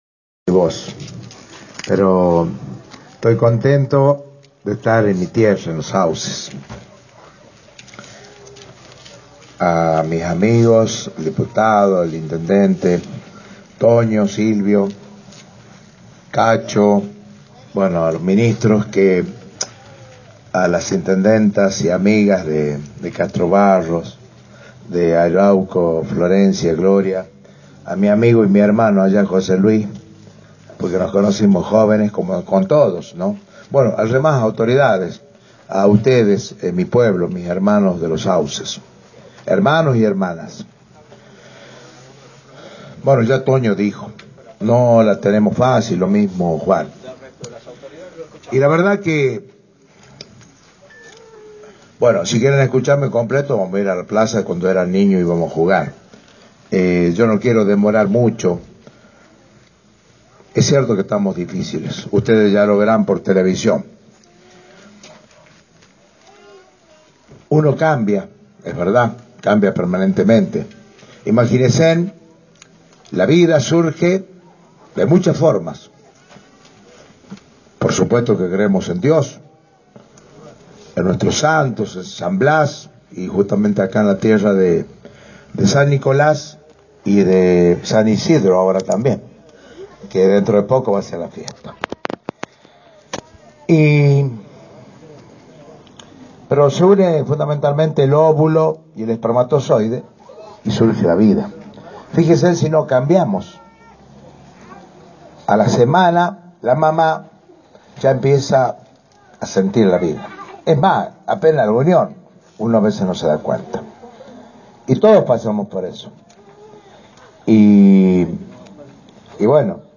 El viernes, el mandatario provincial dejó inaugurada obras públicas en el departamento San Blas de Los Sauces, en donde entregó viviendas rurales en la localidad de Alpasinche e inauguró la refacción y la puesta en valor de la plaza Islas Malvinas en la localidad de Schaqui.